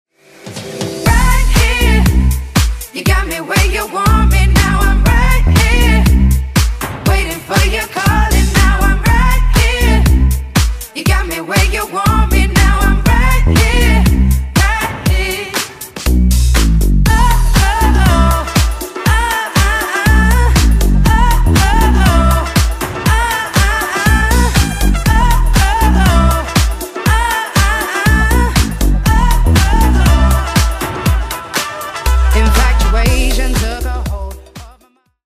Elektronisk musik, Android